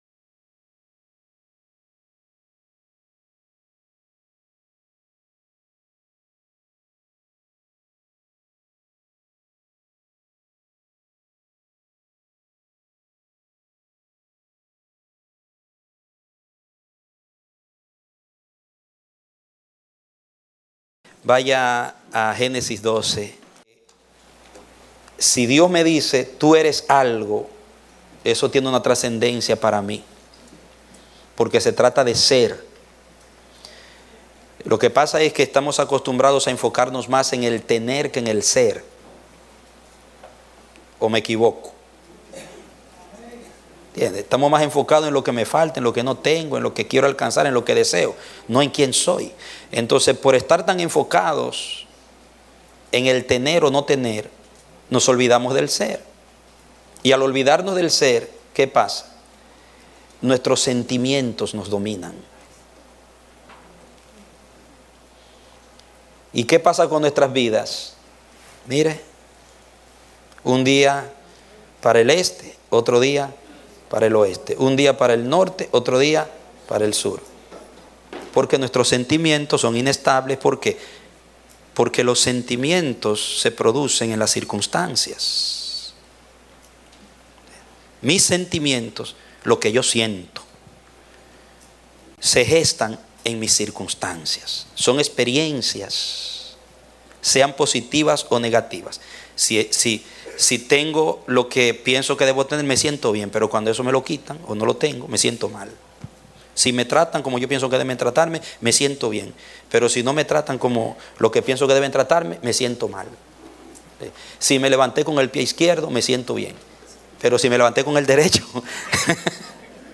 A mensaje from the serie "Eres una Bendición."